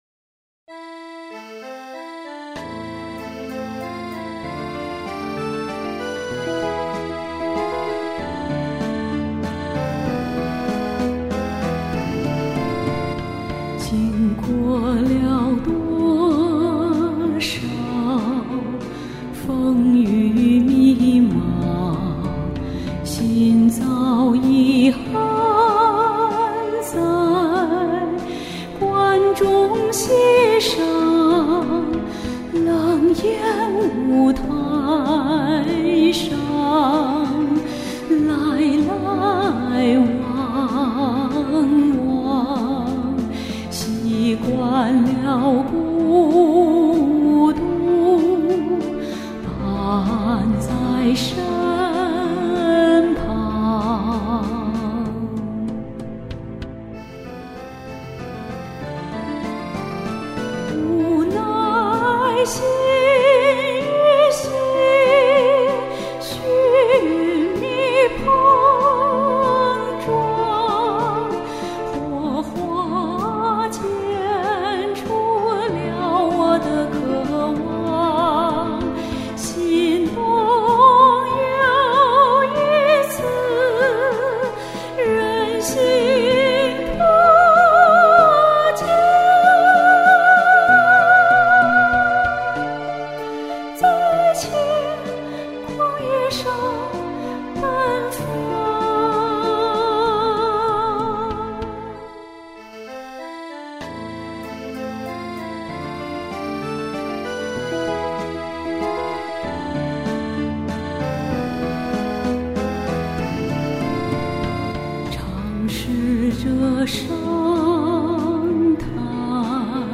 一首深沉的歌，忧伤中带着希望。
好漂亮的音色
尤如风中飘来的轻语述情，优美动人！